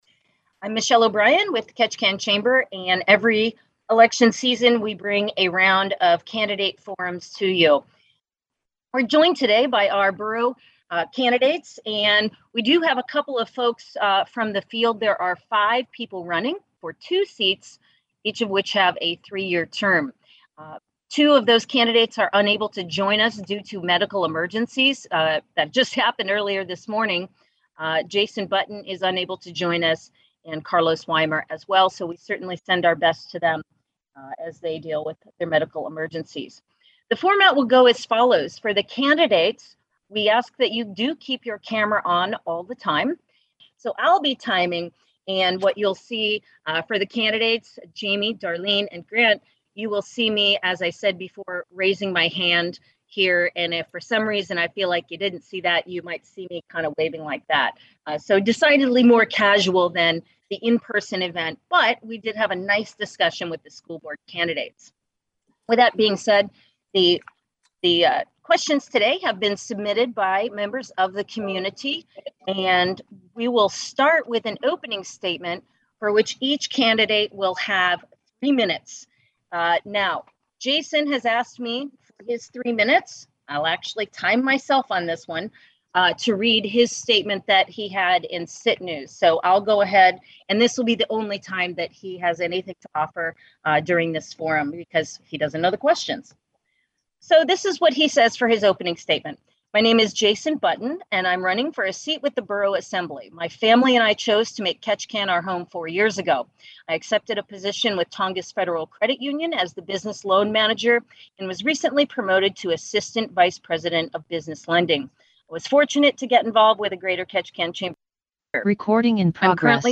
Candidates for Ketchikan’s Borough Assembly shared their views in a forum hosted by the Greater Ketchikan Chamber of Commerce in mid-September.